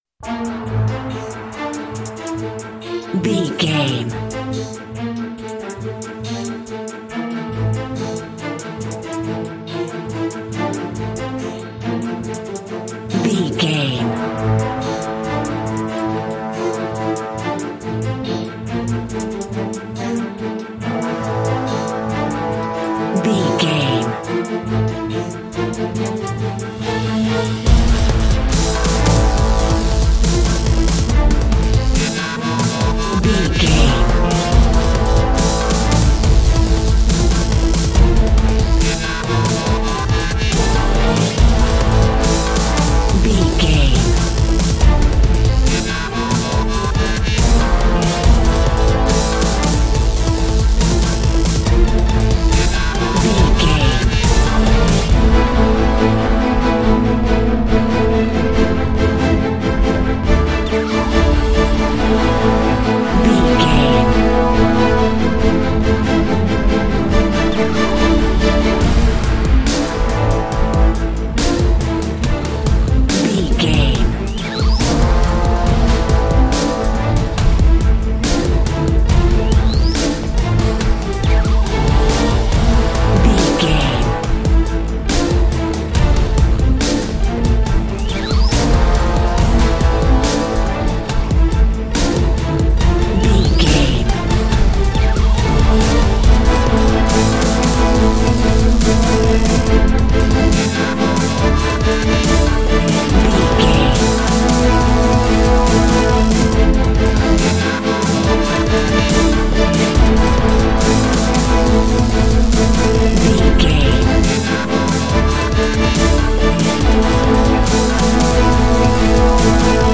Fast paced
In-crescendo
Aeolian/Minor
strings
drums
orchestral
orchestral hybrid
dubstep
aggressive
energetic
intense
bass
synth effects
wobbles
driving drum beat
epic